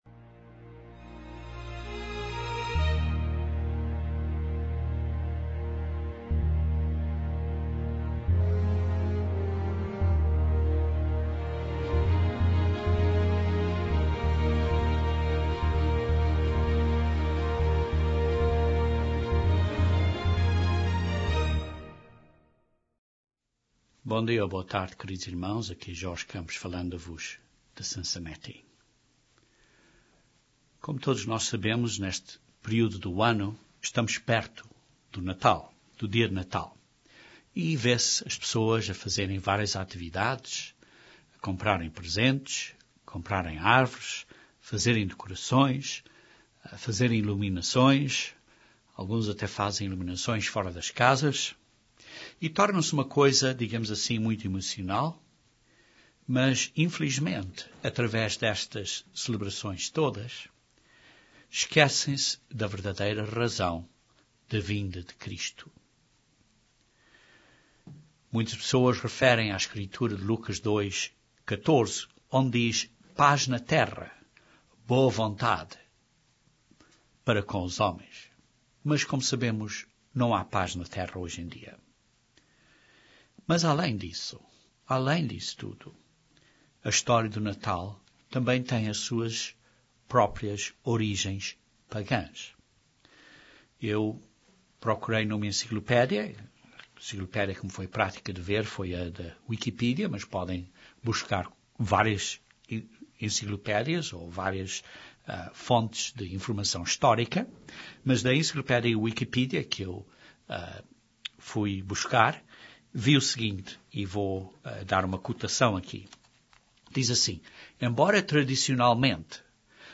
Os acontecimentos à volta do nascimento de Jesus Cristo apontam para o Messias, o Rei dos Reis do vindouro Reino de Deus que reinará na Terra. Este sermão descreve estes acontecimentos e mostra como o entendimento que as pessoas têm escondem este grande significado.